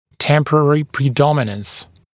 [‘tempərərɪ prɪ’dɔmɪnəns][‘тэмпэрэри при’доминэнс]временное преобладание